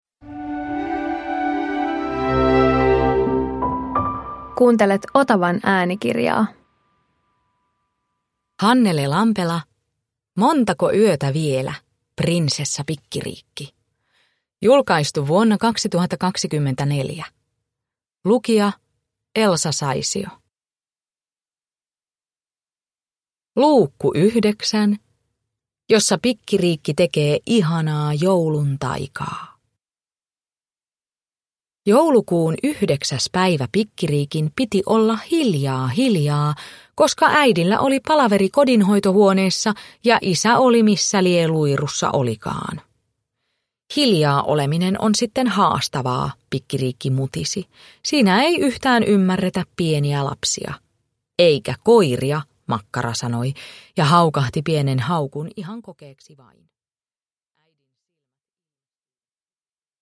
Montako yötä vielä, Prinsessa Pikkiriikki 9 – Ljudbok